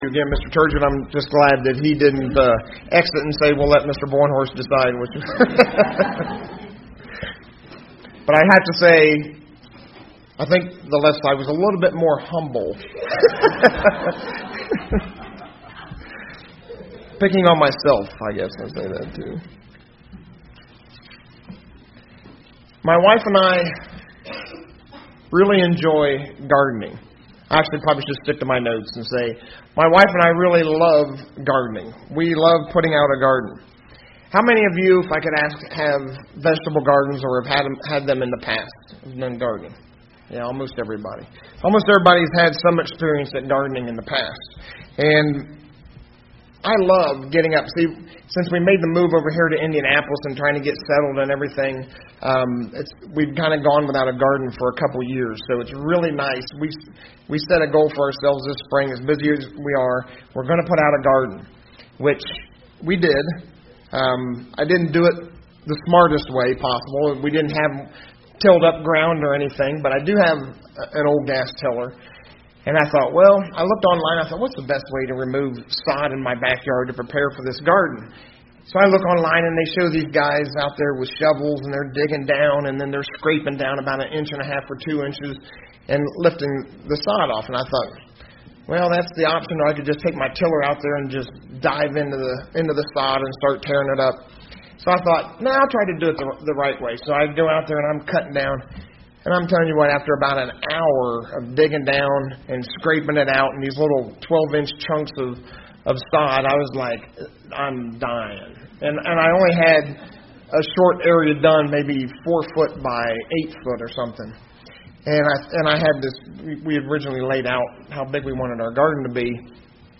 Given in Indianapolis, IN Ft. Wayne, IN